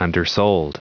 Prononciation du mot undersold en anglais (fichier audio)
Prononciation du mot : undersold